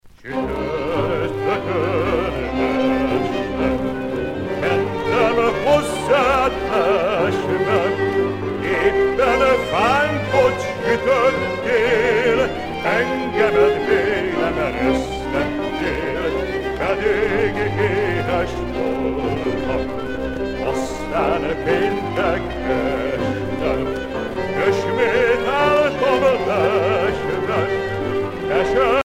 danse : csárdás (Hongrie)